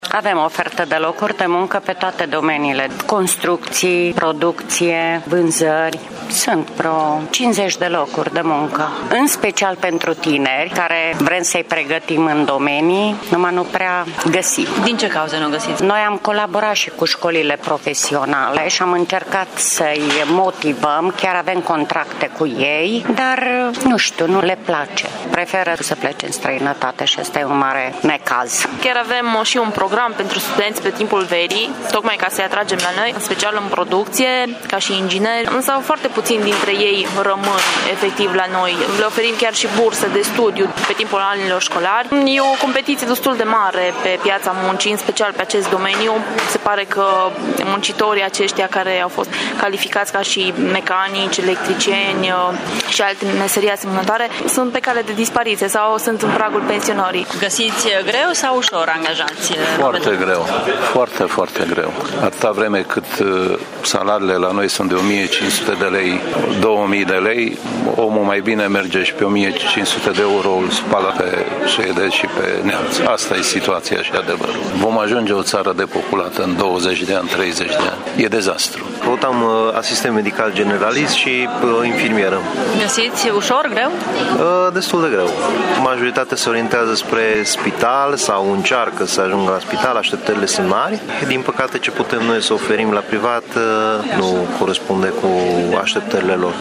Locurile de muncă sunt oferite de circa 80 de angajatori mureșeni. Mulți dintre ei recunosc că au dificultăți să-și găsească angajați, chiar și tineri debutanți pe care să-i instruiască pe parcurs: